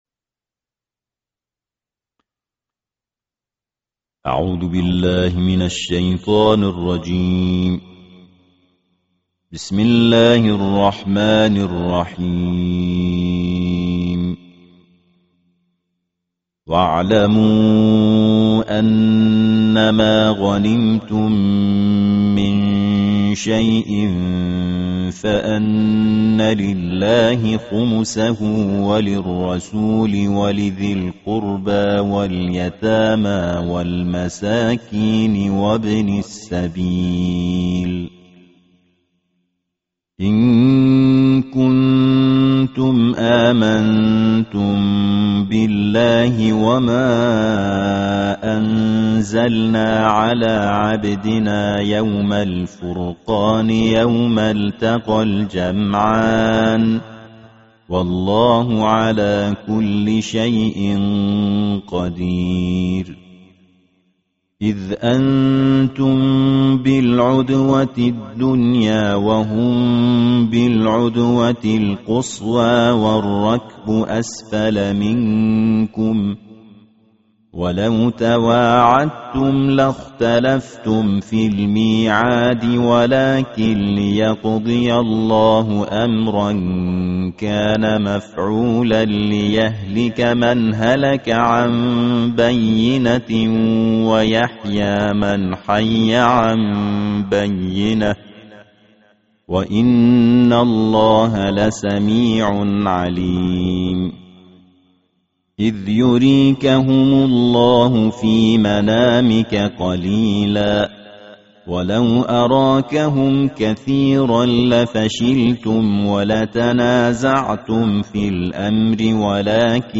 Tilawar Kur'ani Juzu'i Na 10